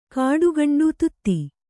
♪ kāḍu gaṇḍu tutti